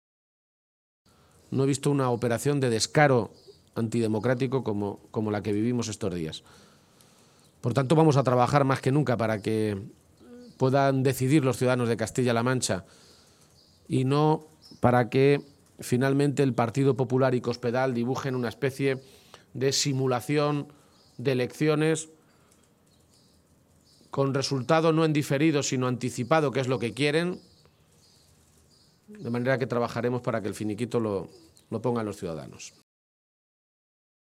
El secretario general del PSOE de Castilla-La Mancha, Emiliano García-Page, ha protagonizado esta mañana un desayuno informativo en Toledo con medios de comunicación en el que ha anunciado la presentación de un recurso ante el Tribunal Constitucional contra la reforma del Estatuto de Autonomía de Castilla-La Mancha aprobada ayer en el Senado solo con los votos del PP “para parar este verdadero atropello, este verdadero pucherazo electoral que quiere dar Cospedal y que es el único motivo de este cambio estatutario”.